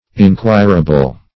Meaning of inquirable. inquirable synonyms, pronunciation, spelling and more from Free Dictionary.
Search Result for " inquirable" : The Collaborative International Dictionary of English v.0.48: Inquirable \In*quir"a*ble\, a. [Cf. OF. enquerable.] Capable of being inquired into; subject or liable to inquisition or inquest.